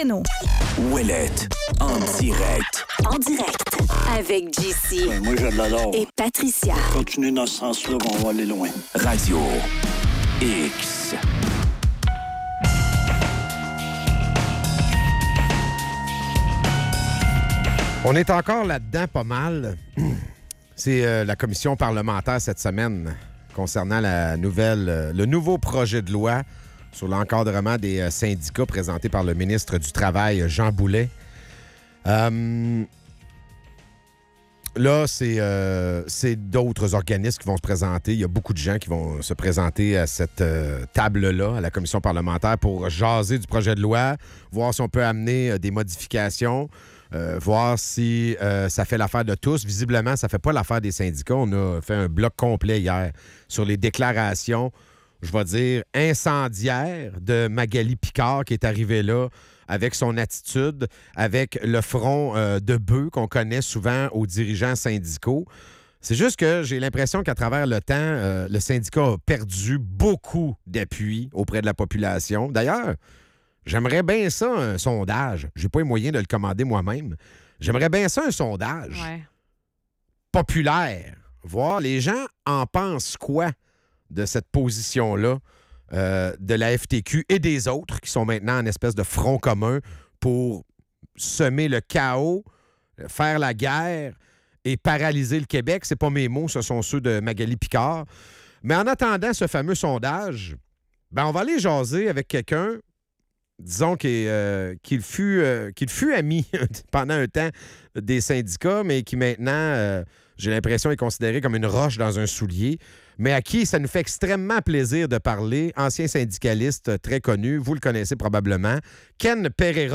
En entrevue